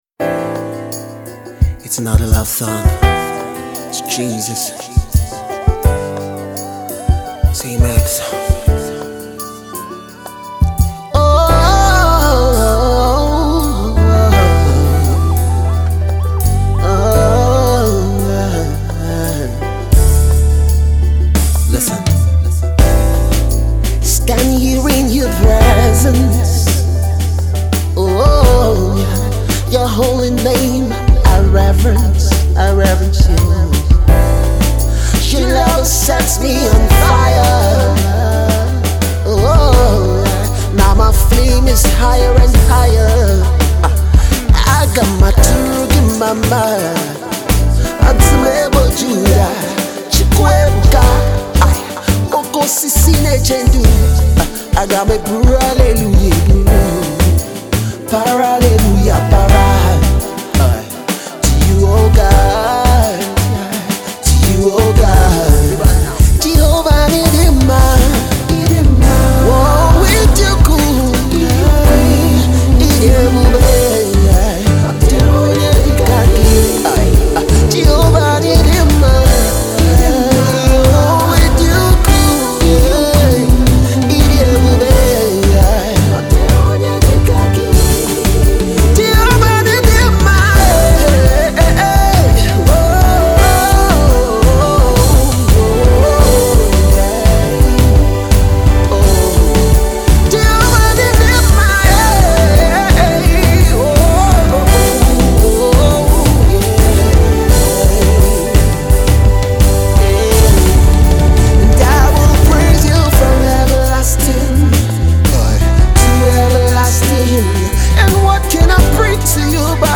Gospel music
worship song
a beautiful sing along worship tune